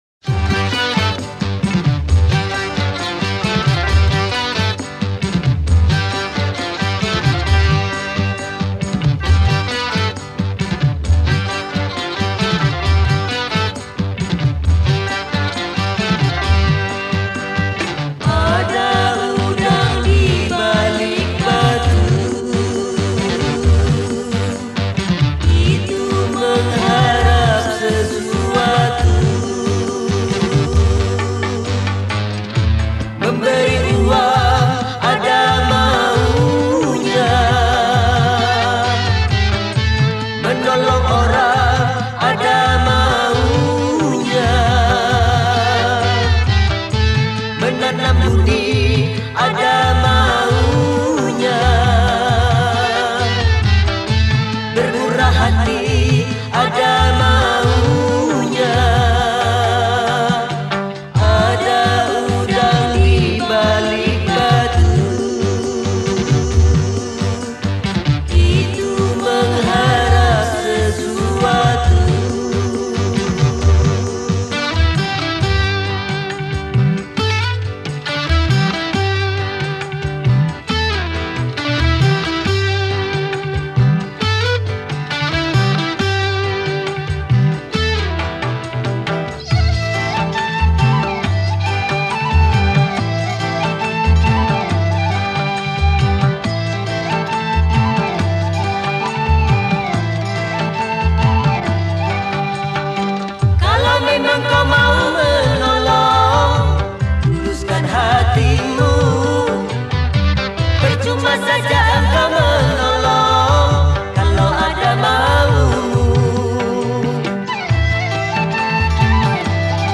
Genre Musik                        : Dangdut